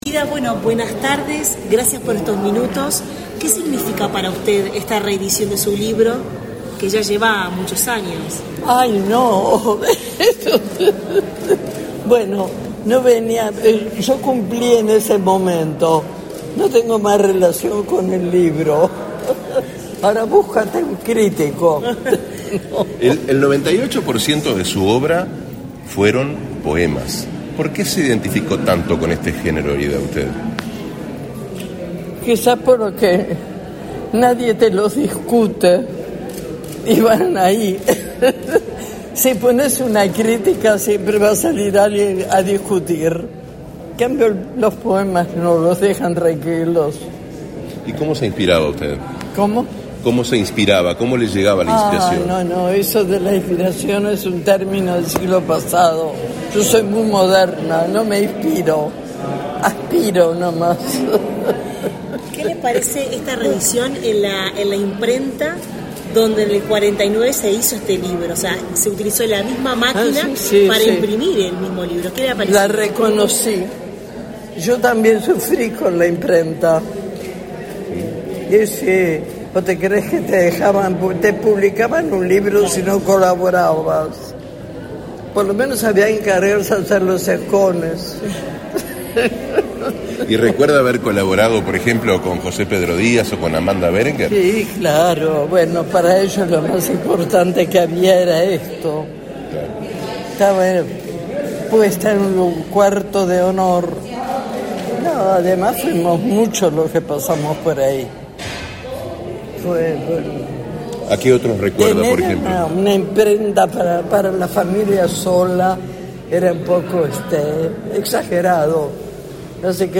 Declaraciones a la prensa de la poeta uruguaya, Ida Vitale
Declaraciones a la prensa de la poeta uruguaya, Ida Vitale 29/11/2023 Compartir Facebook X Copiar enlace WhatsApp LinkedIn La Biblioteca Nacional de Uruguay presentó, este 29 de noviembre, el libro "La luz de esta memoria", de la poeta uruguaya Ida Vitale, en homenaje al centenario de su nacimiento. En la oportunidad, Vitale realizó declaraciones.